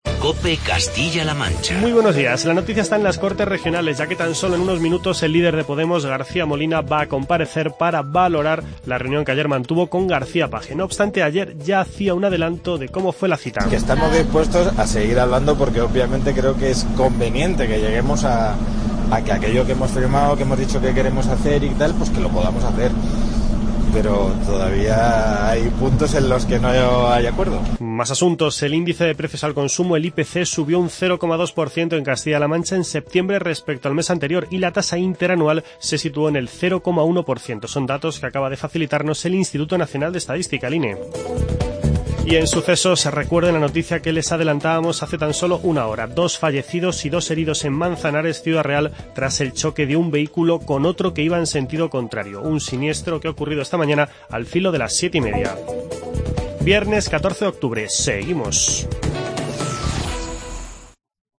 Informativo COPE Castilla-La Mancha